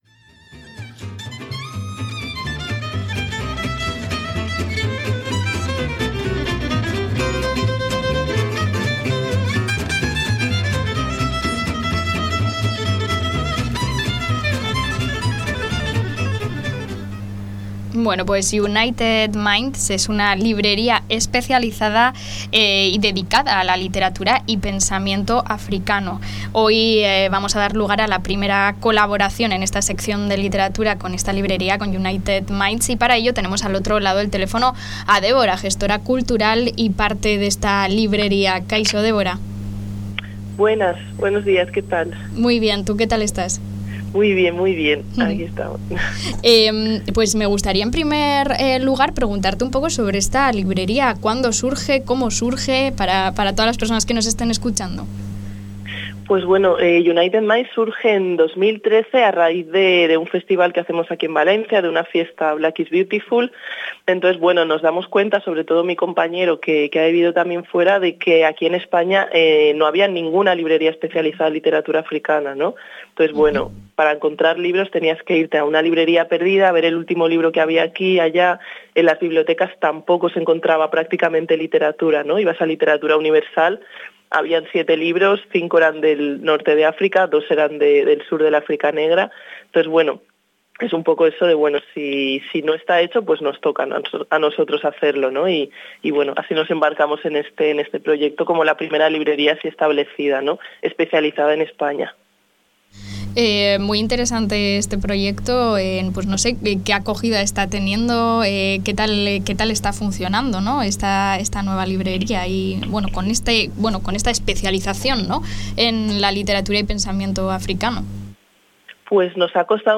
Aquí podéis escuchar la entrevista completa: